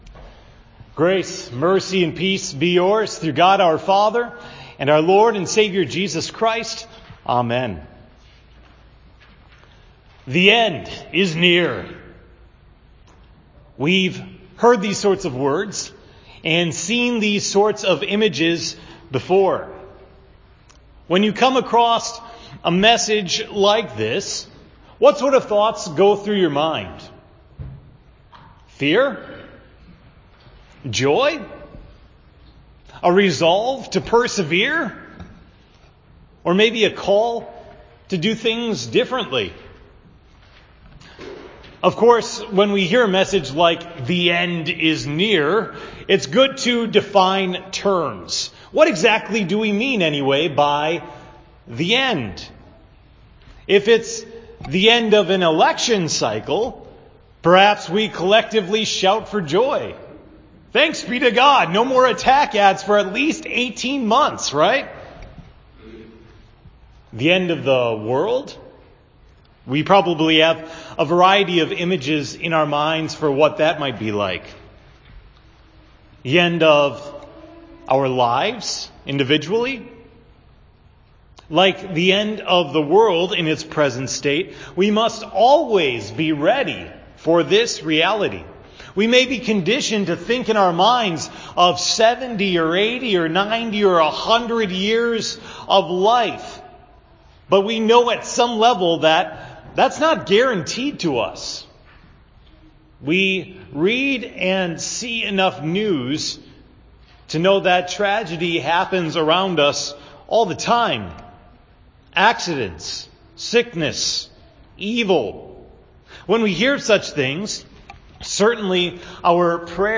The sermon for November 18, 2018 at Hope Text: Daniel 12:1-3